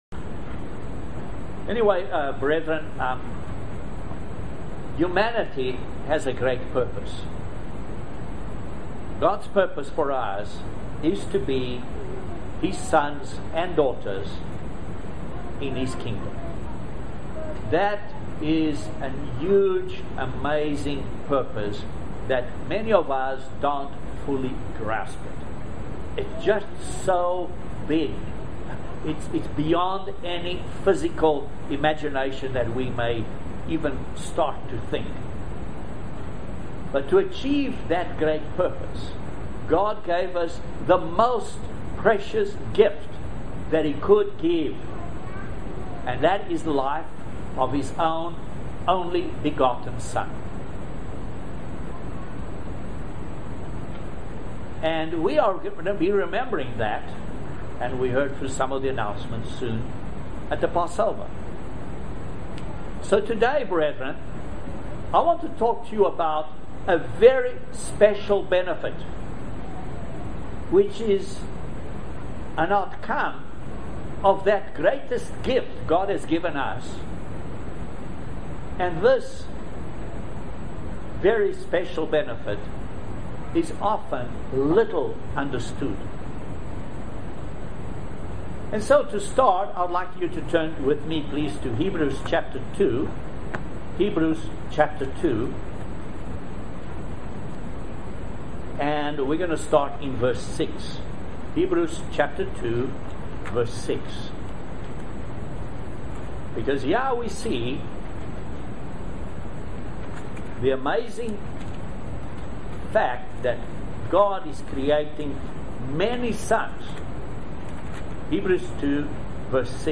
Please join us for this very interesting video sermon on the subject of God's Righteousness.